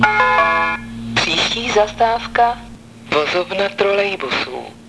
Hlášení zastávek ústecké MHD
Většina nahrávek byla pořízena ve voze ev.č. 516.
Na této stránce jsou kvalitnější zvuky, původní web obsahuje nahrávky horší zvukové kvality.